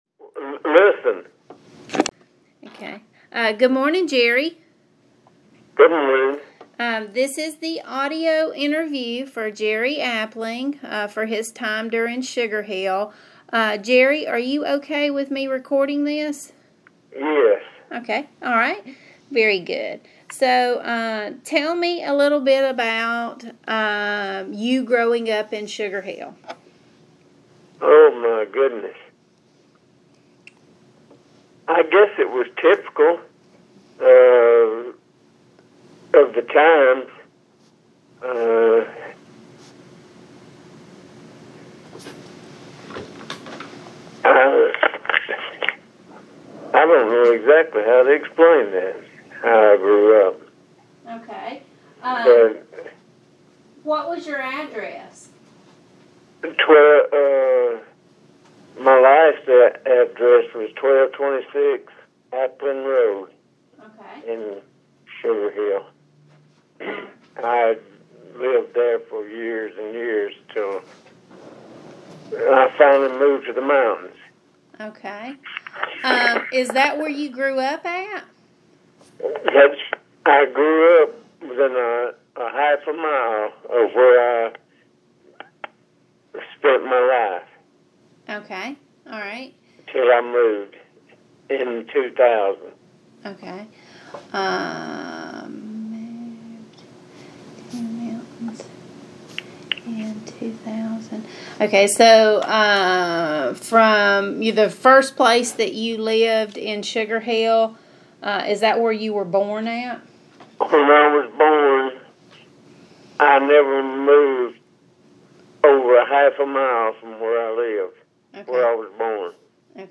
Oral histories
via telephone